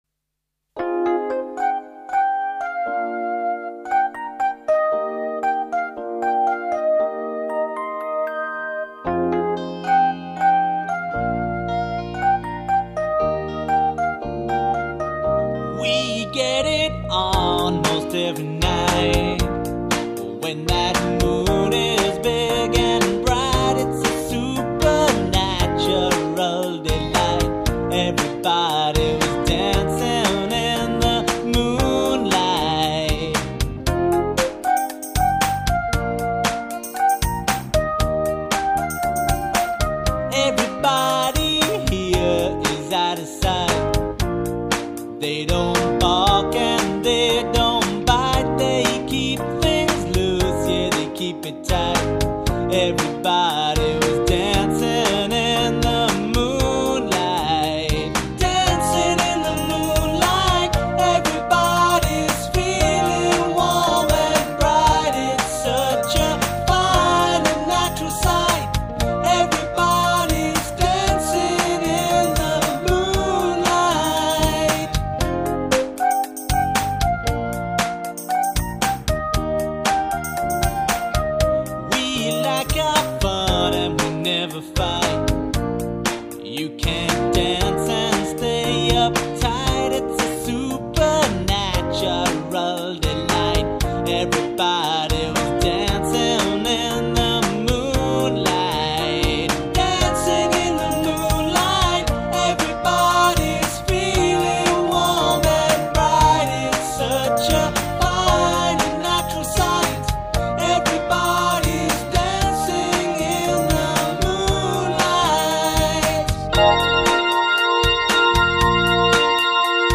There are 2 songs here, each with a LoFi and HiFi version.